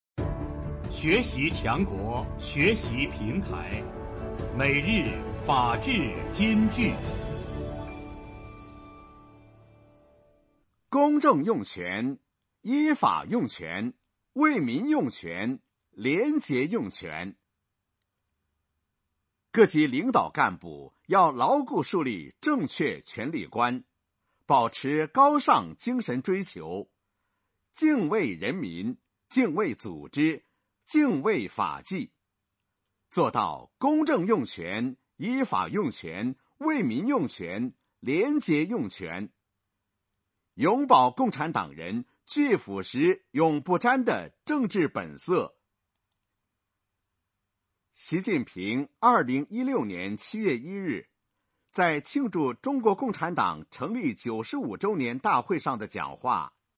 每日法治金句（朗读版）|公正用权、依法用权、为民用权、廉洁用权 _ 创建模范机关 _ 福建省民政厅